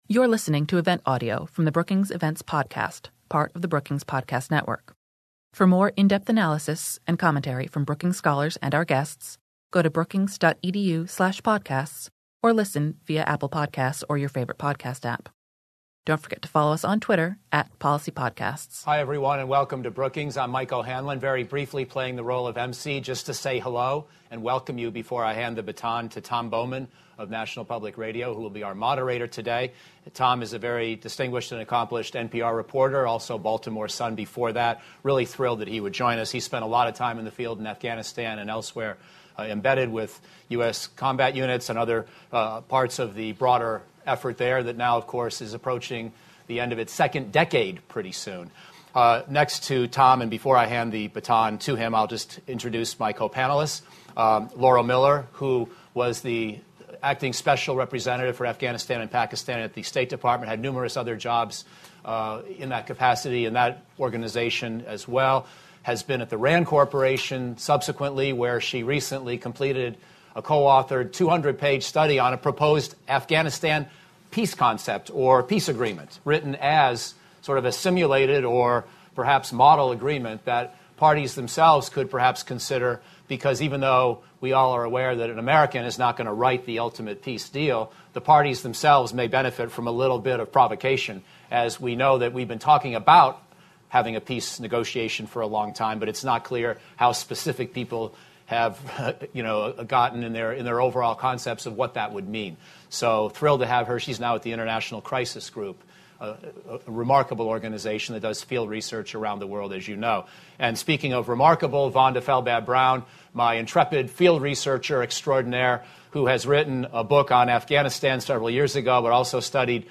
On December 16, The Brookings Institution hosted an event to discuss the state of affairs in Afghanistan, the prospect for renewed efforts at negotiations, and possible for U.S. policymakers.